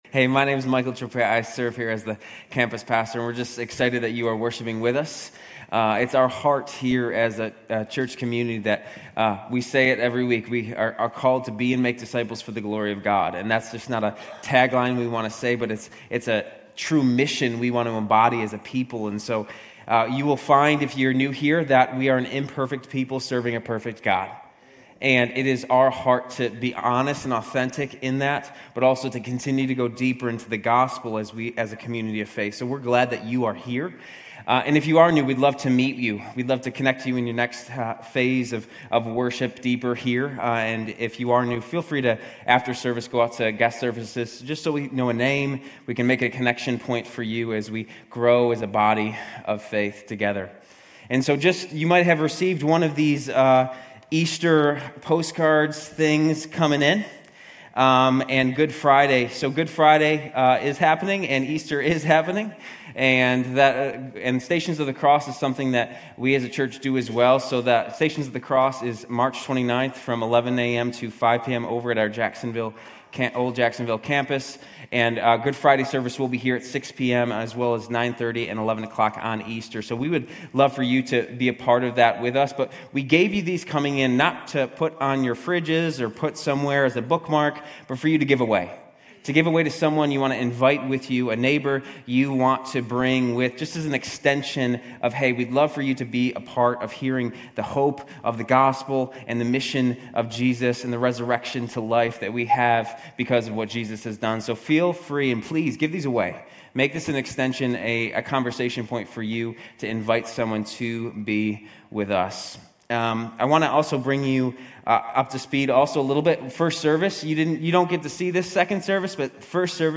Grace Community Church University Blvd Campus Sermons 180: John 3 Mar 11 2024 | 00:40:18 1x 00:00 / 00:40:18 Subscribe Share RSS Feed Share Link Embed